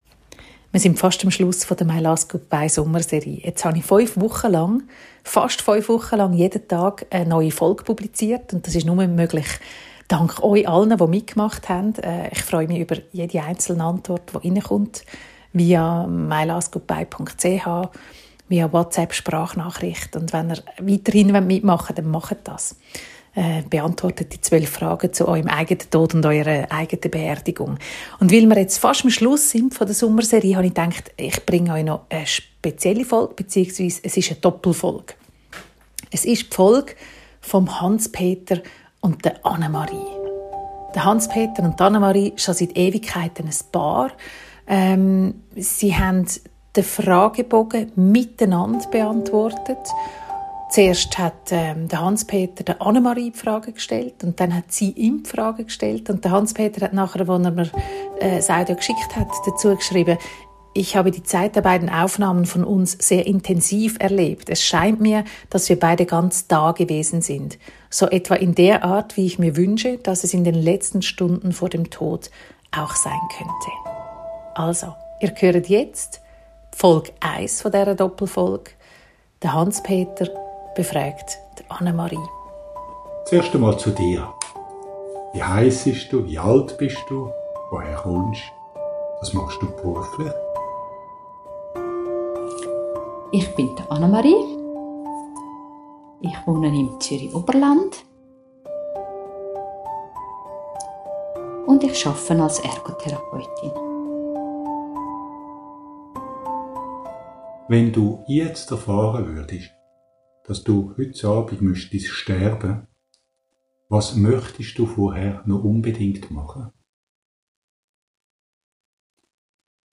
Dabei fliessen Tränen. Es wird geschwiegen. Es werden Gedichte rezitiert.
Beschreibung vor 2 Jahren Den Abschluss der täglichen «pur und ungeschnitten»-Sommerserie macht eine Doppelfolge.